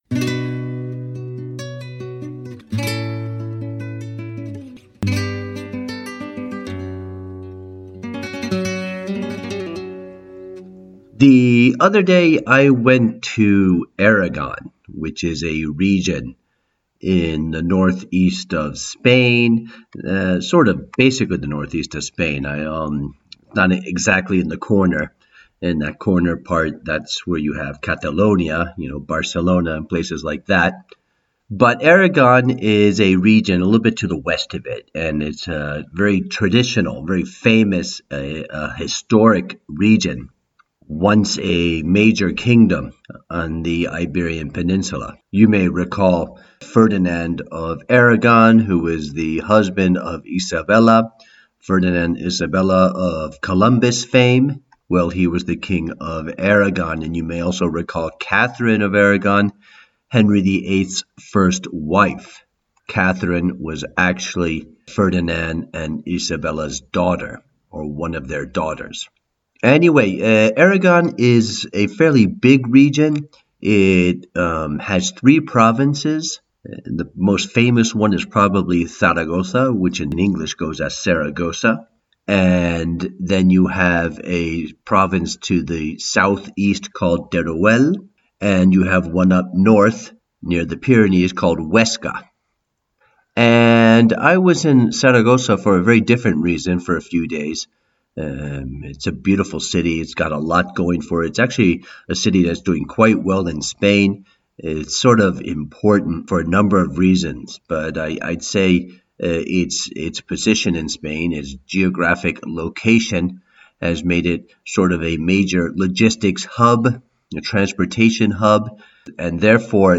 Today we start another series, a slightly more freewheelin’ and off-the-cuff talk about Spain, places we’ve bveen to, things we are doing, culture and always with a bit of history thrown in.